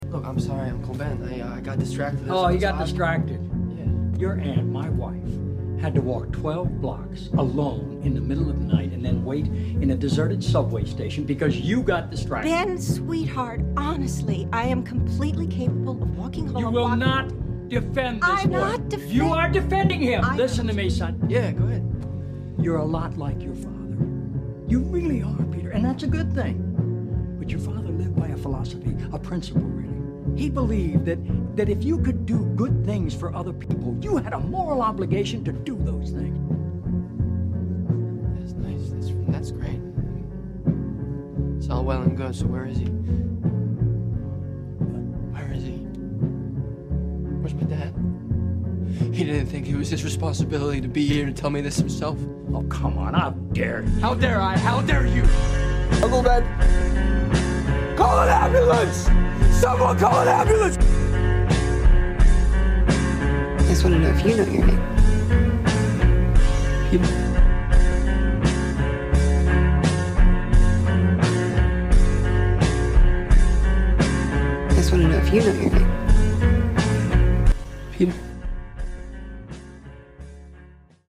instrumental:slowed